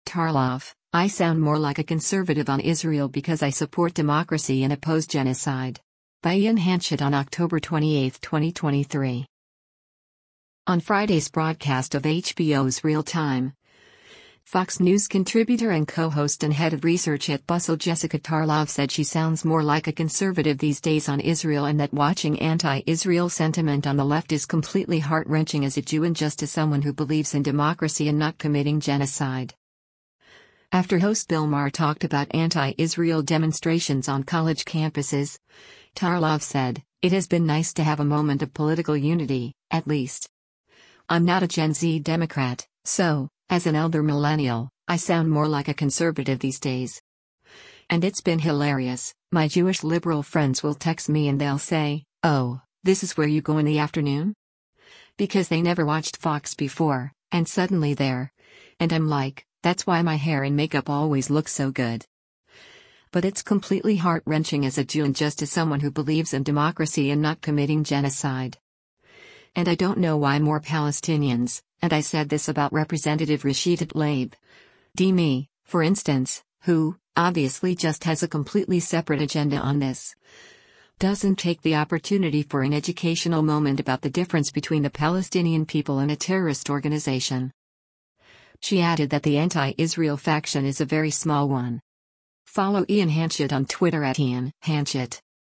On Friday’s broadcast of HBO’s “Real Time,” Fox News Contributor and co-host and Head of Research at Bustle Jessica Tarlov said she sounds “more like a conservative these days” on Israel and that watching anti-Israel sentiment on the left is “completely heart-wrenching as a Jew and just as someone who believes in democracy and not committing genocide.”